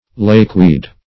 Search Result for " lakeweed" : The Collaborative International Dictionary of English v.0.48: Lakeweed \Lake"weed`\, n. (Bot.)
lakeweed.mp3